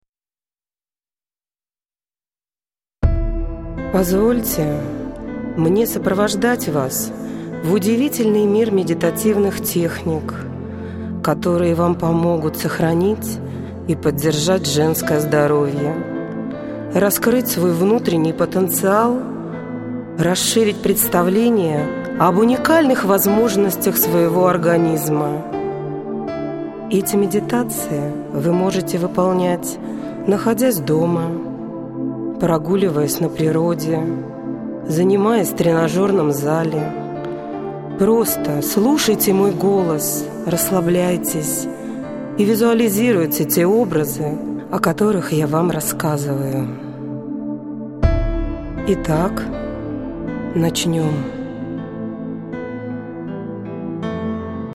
МЕДИТАЦИИ ДЛЯ ЖЕНЩИН
Эти медитации были созданы лично мной для ВАС и воспроизведены на студии звукозаписи.
Все медитации я зачитываю СВОИМ ГОЛОСОМ!